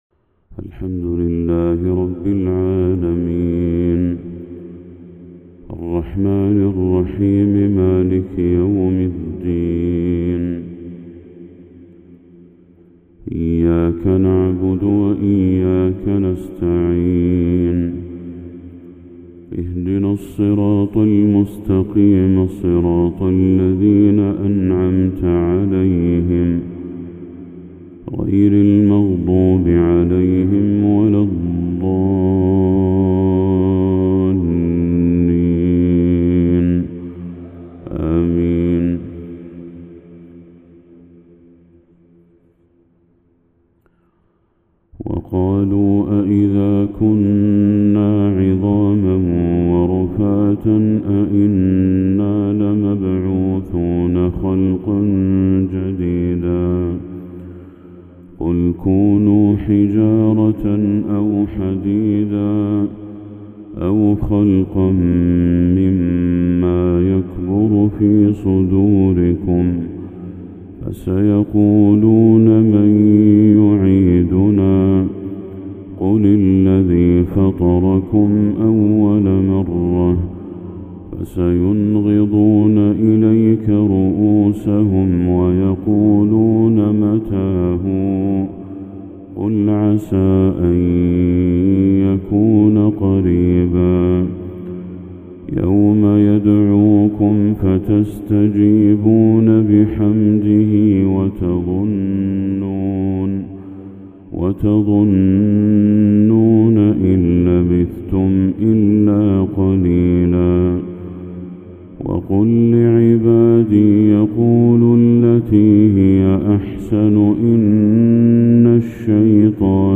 تلاوة من سورة الإسراء للشيخ بدر التركي | فجر 27 ذو الحجة 1445هـ > 1445هـ > تلاوات الشيخ بدر التركي > المزيد - تلاوات الحرمين